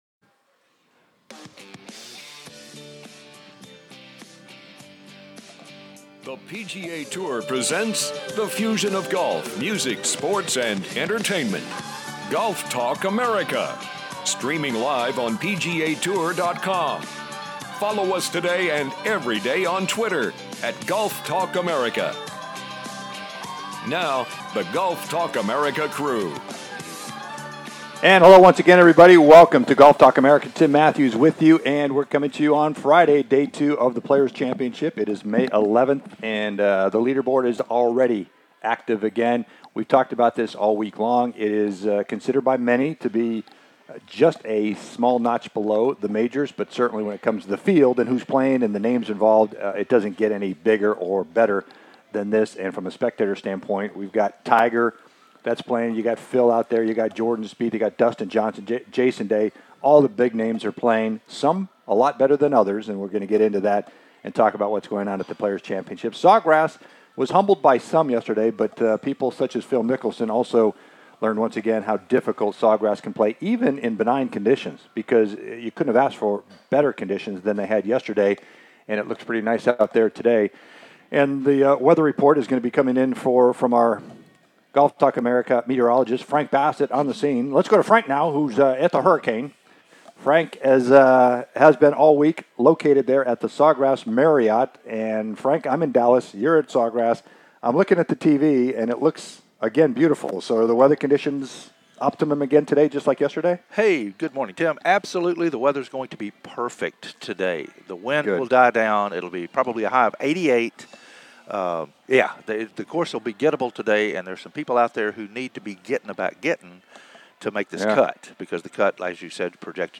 The Players "LIVE" From Sawgrass